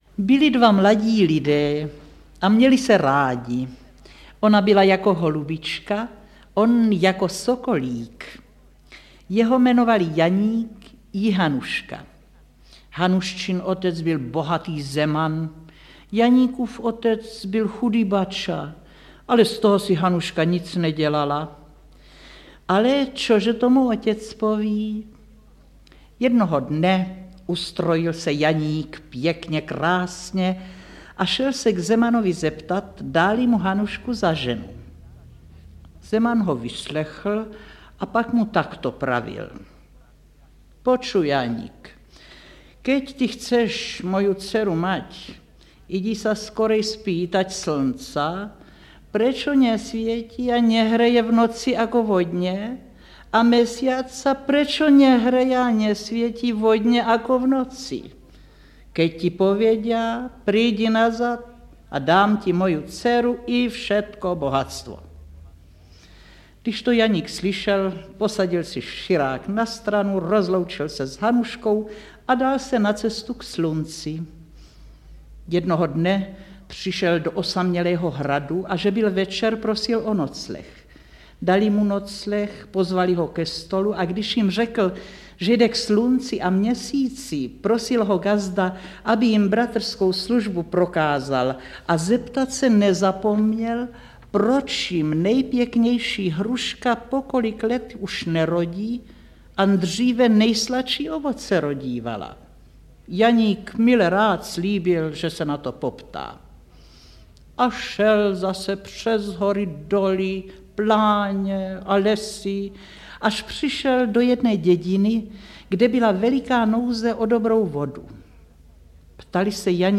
V již 19. dílu řady Album pohádek "Supraphon dětem" opět představujeme známé i méně známé pohádky z archivu Supraphonu. Vracíme se v nich do let padesátých a k legendárním interpretům jako byla Růžena Nasková (Cesta k slunci a měsíci, Křesadlo) či Otýlie Beníšková (Jak se líný synek učil pracovat).